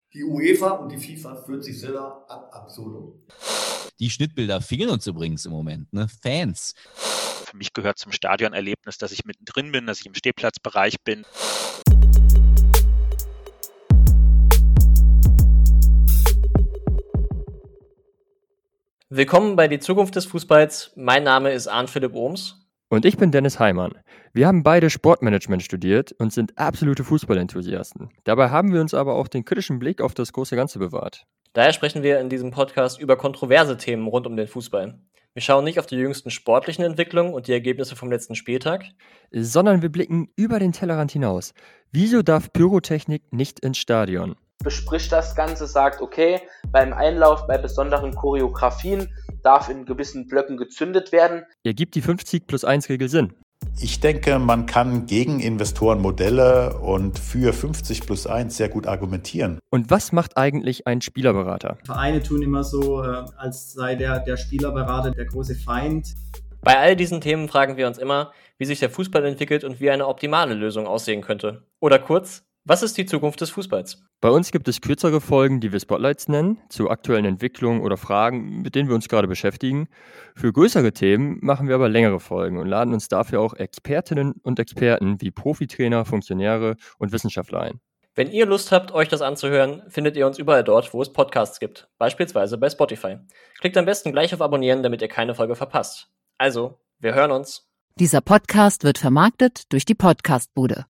Die verwendeten Audio-Snippets unserer Gäste aus der ersten Staffel: